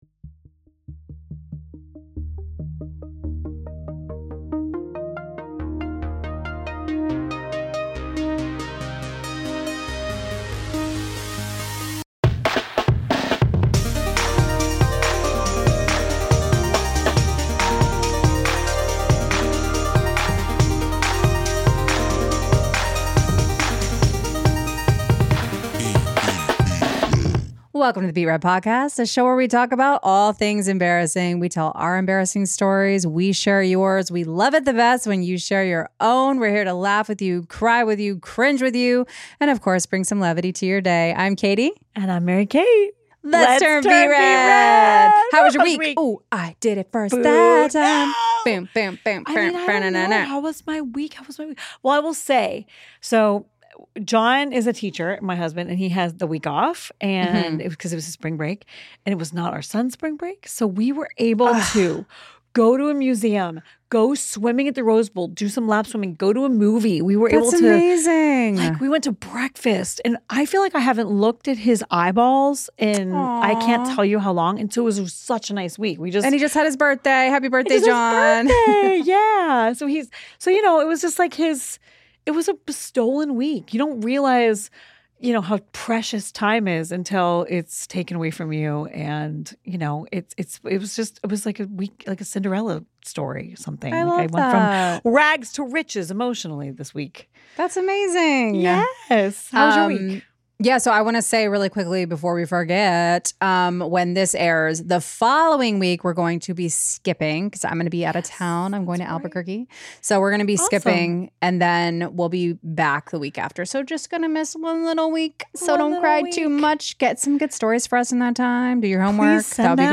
Produced, arranged, edited and mixed in Los Angeles, CA